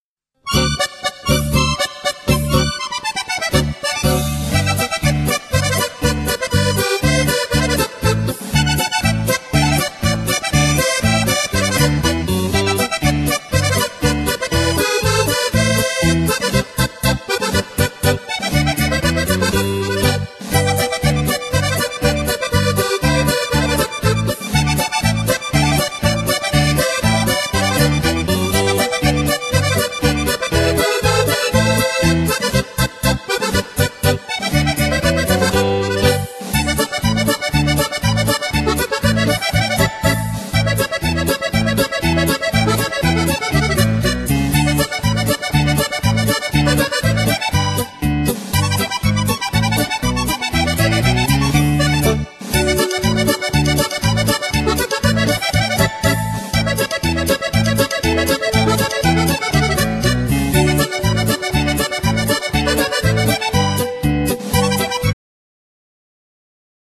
Genere : Lisco folk